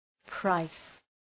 price.mp3